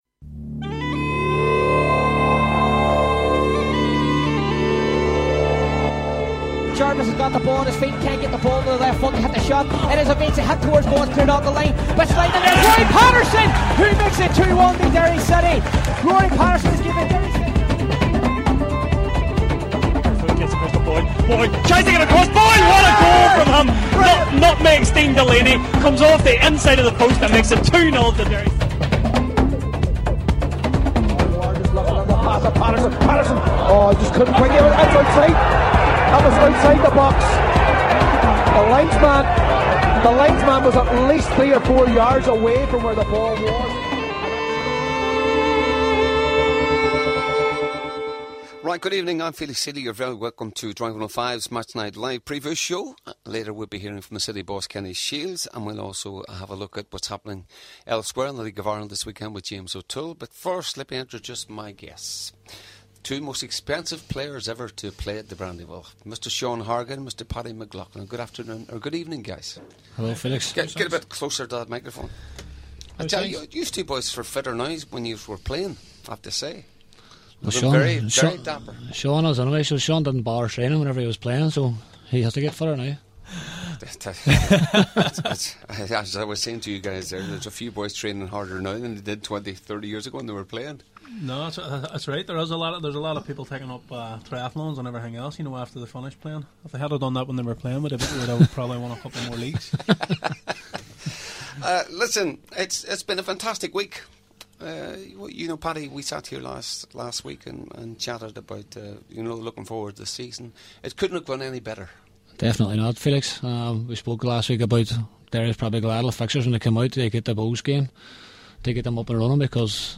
preview show